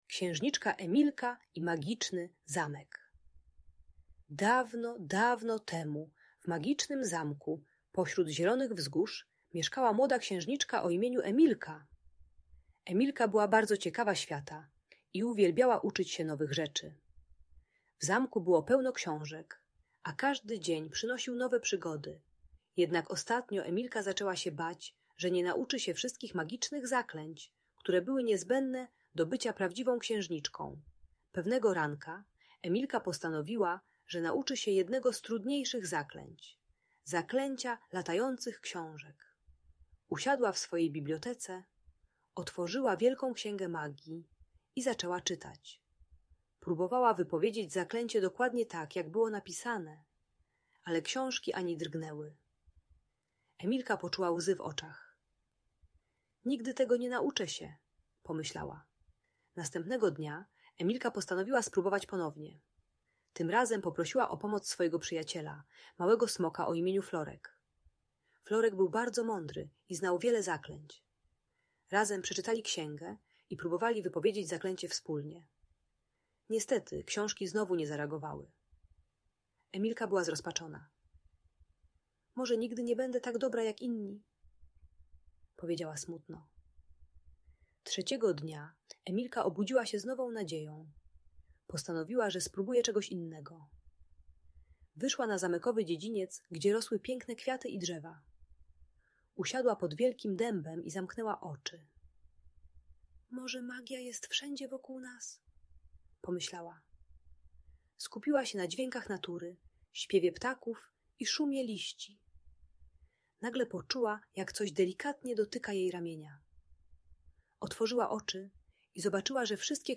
Księżniczka Emilka i Magiczny Zamek - Lęk wycofanie | Audiobajka